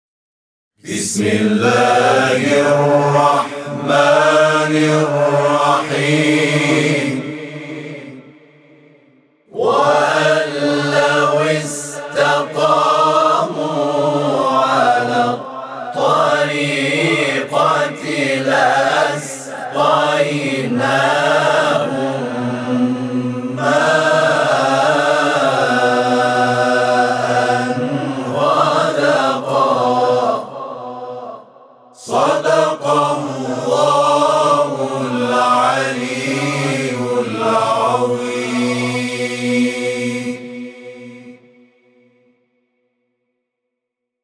صوت همخوانی آیه 16 سوره جن از سوی گروه تواشیح «محمد رسول‌الله(ص)»